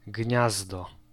Ääntäminen
France: IPA: [ni]